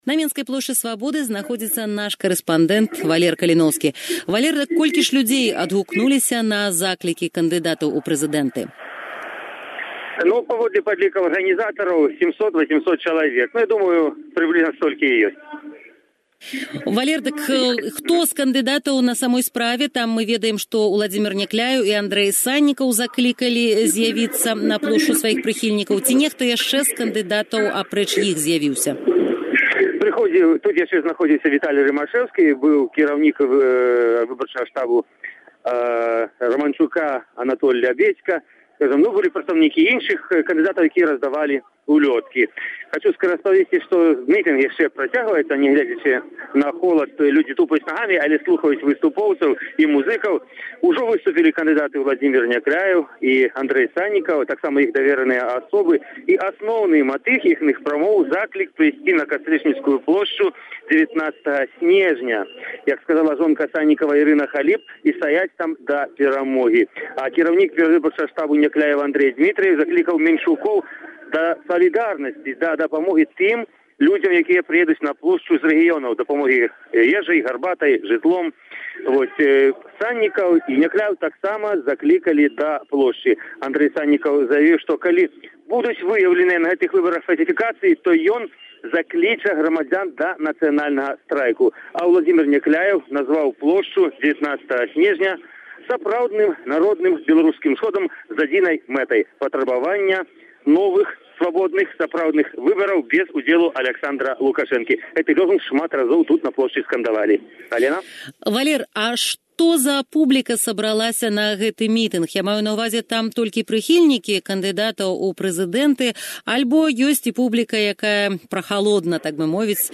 перадае зь месца падзеяў.